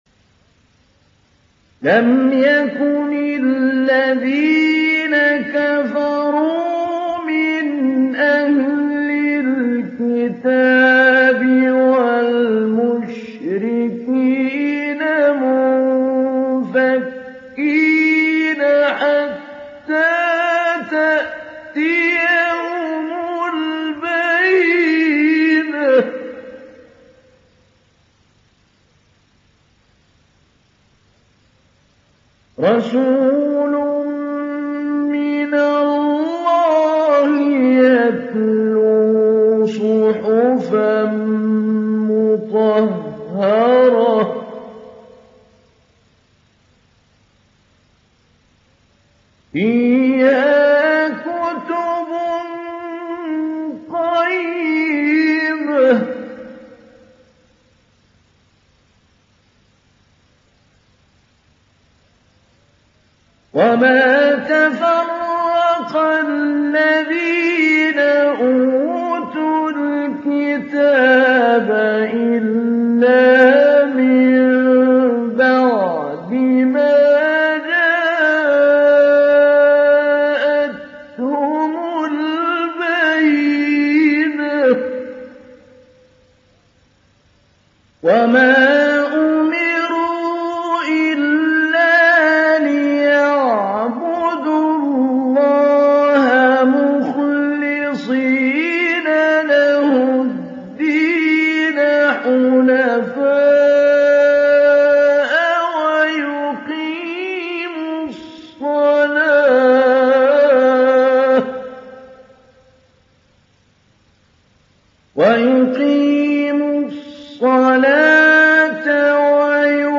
دانلود سوره البينه mp3 محمود علي البنا مجود روایت حفص از عاصم, قرآن را دانلود کنید و گوش کن mp3 ، لینک مستقیم کامل
دانلود سوره البينه محمود علي البنا مجود